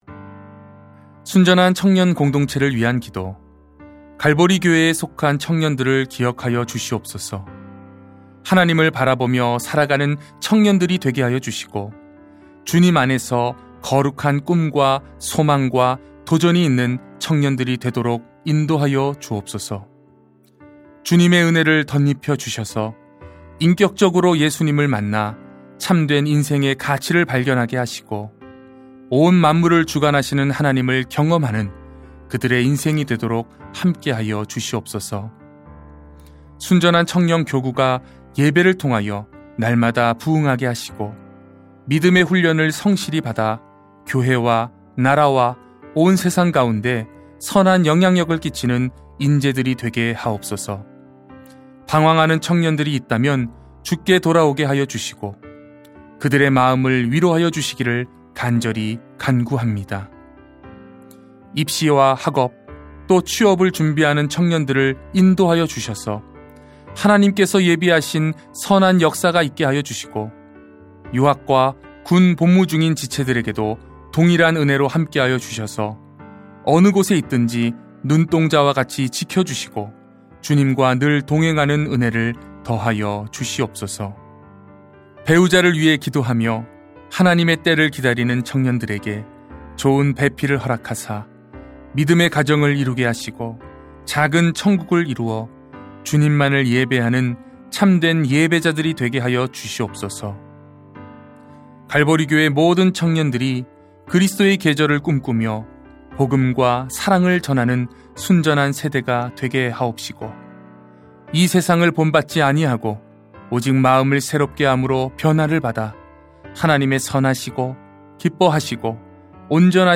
중보기도